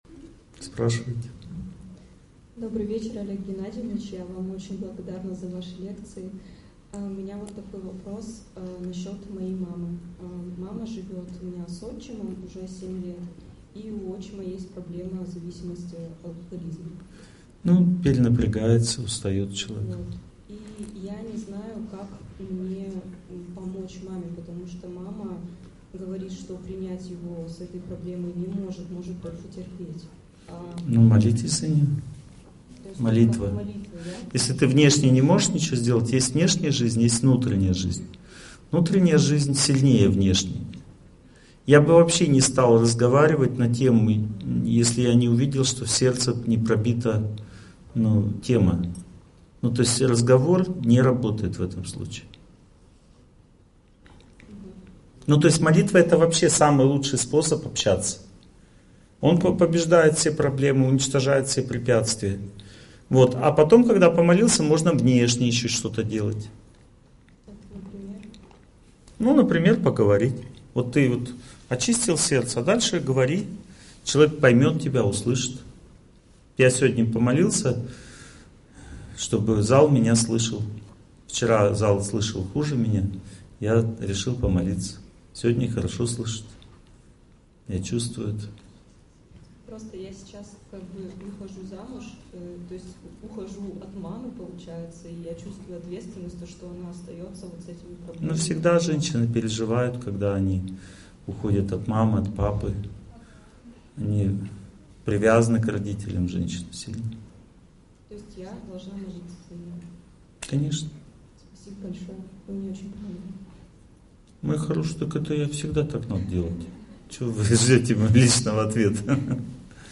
Znaki-sudbi-Kak-ponyat-chto-menya-zhdet-Lekciya-3.mp3